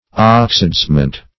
Oxidizement \Ox"i*dize`ment\